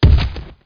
boulder2.mp3